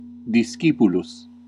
Ääntäminen
Synonyymit audītor Ääntäminen Classical: IPA: /disˈki.pu.lus/ Haettu sana löytyi näillä lähdekielillä: latina Käännös Ääninäyte Substantiivit 1. student UK US 2. pupil US 3. disciple US 4. apprentice Suku: m .